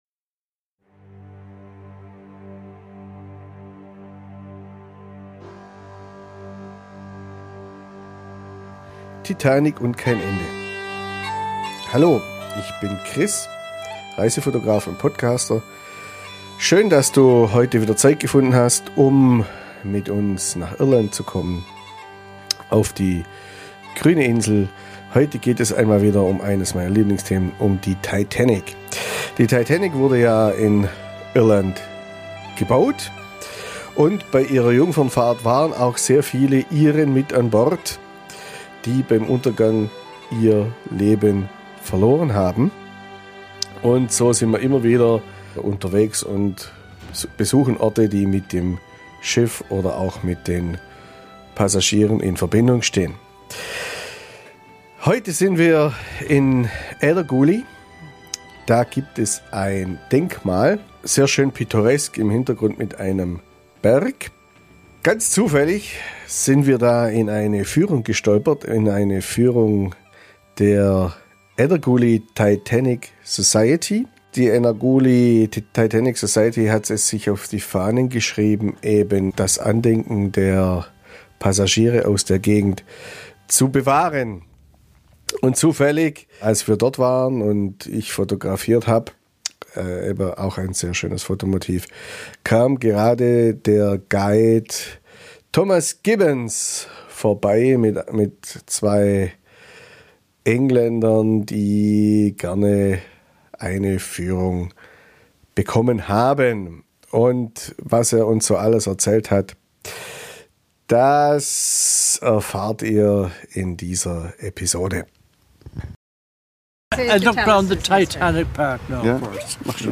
Ganz zufällig sind wir da in eine Führung gestolpert, in eine Führung der Eddergooly Titanic Society.